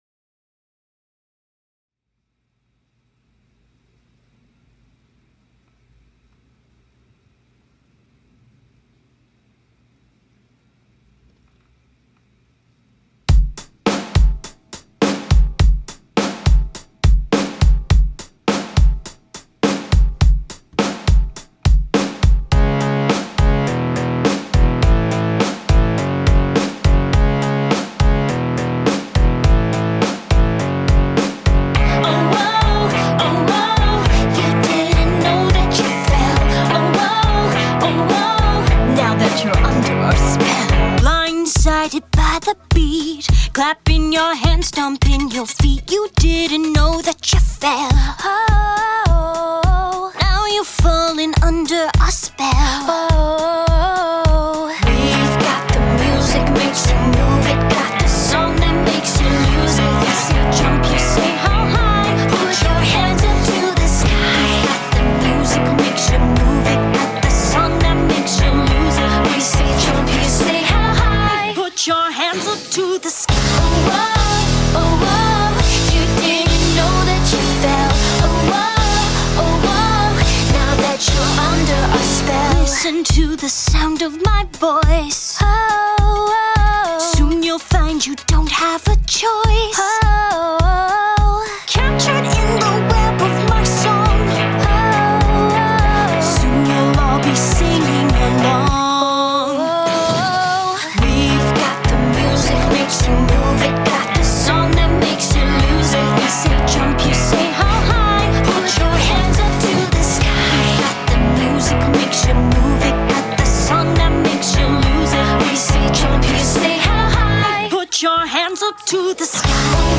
LaunchPad Pro Cover